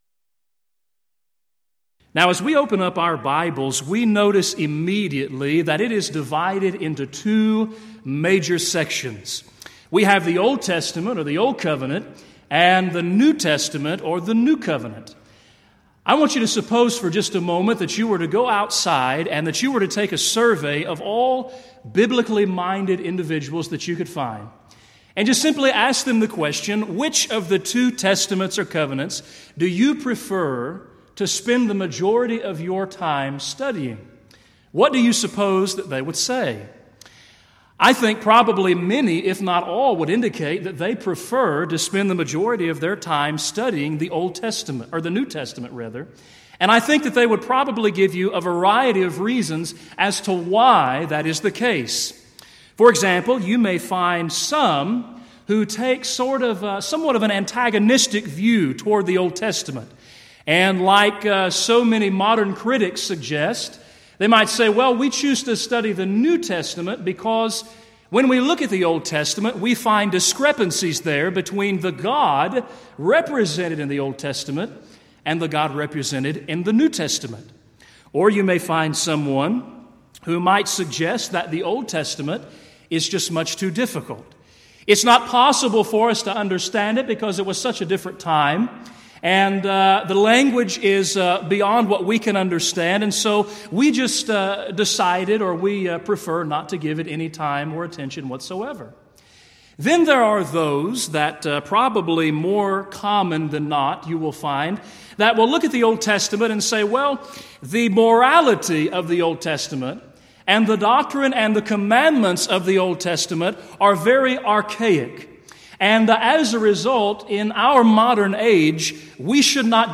Alternate File Link File Details: Series: Southwest Lectures Event: 32nd Annual Southwest Lectures Theme/Title: Why Do We...